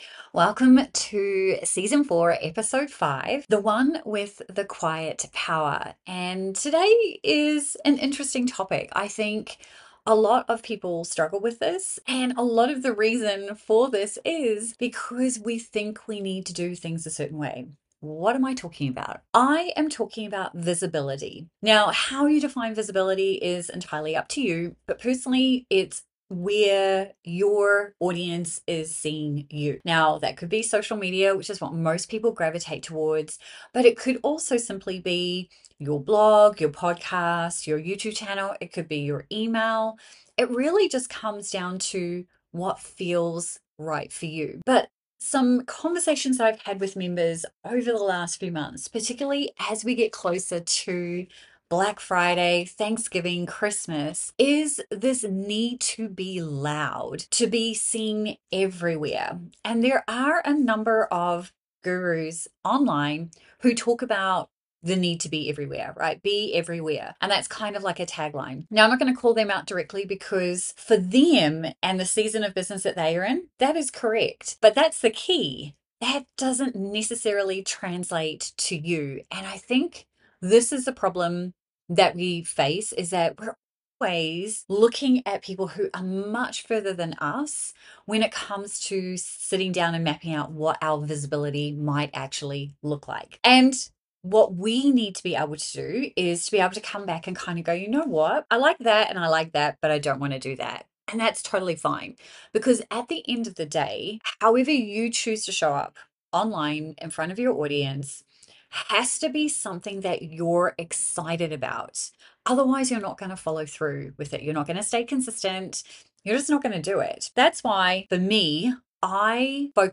Recorded in 1998